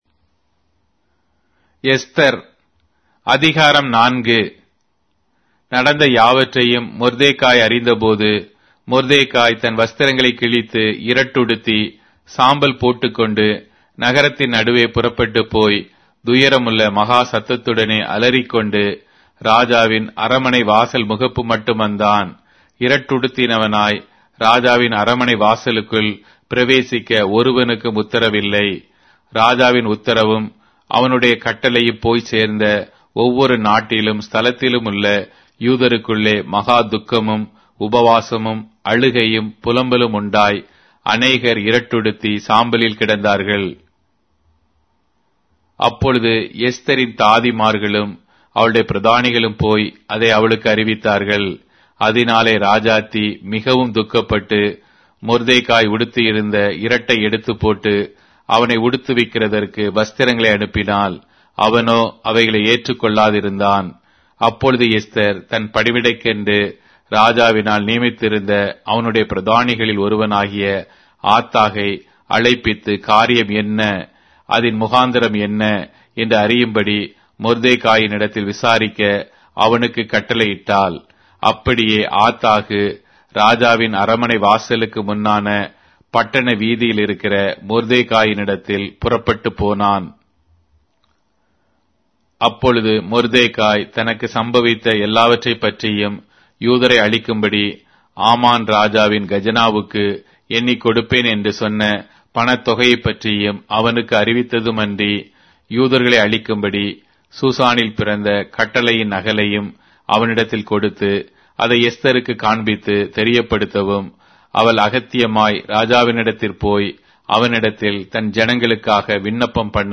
Tamil Audio Bible - Esther 2 in Ylt bible version